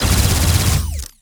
GUNAuto_Plasmid Machinegun C Burst_03_SFRMS_SCIWPNS.wav